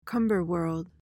PRONUNCIATION:
(KUHM-buhr-wurld)